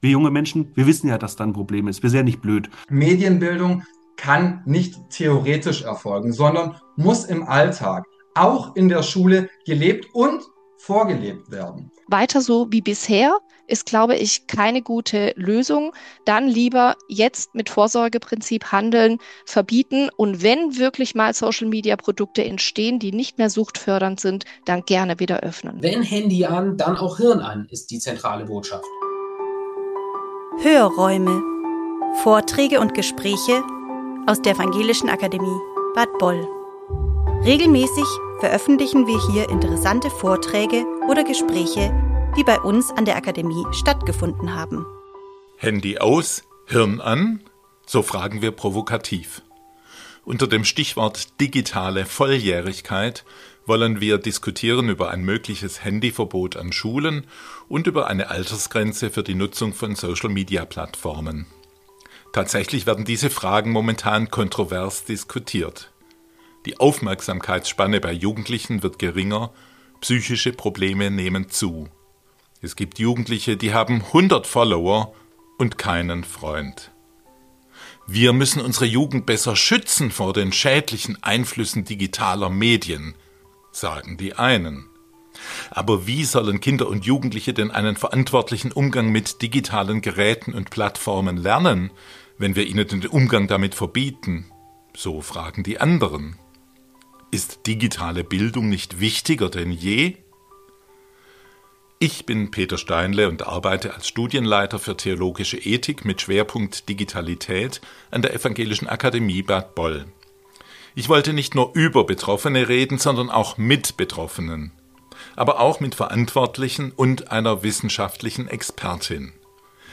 Eine konzentrierte, differenzierte Debatte über Chancen und Grenzen digitaler Medien und darüber, wie ein bewusstes und verantwortungsvolles Aufwachsen im Digitalen möglich wird.